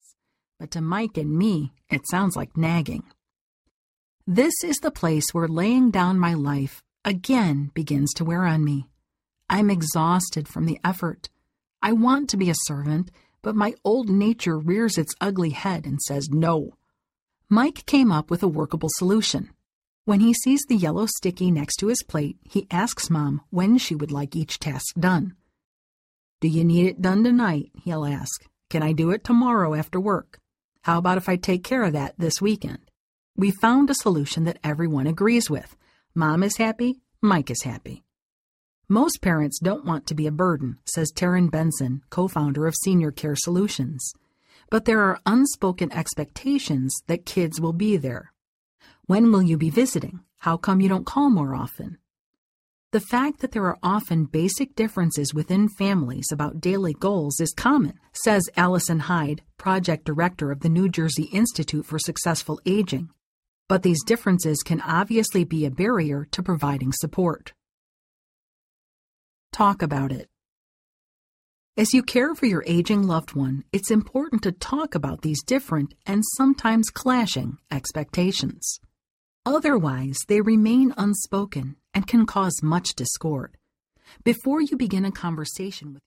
The Caregiving Season Audiobook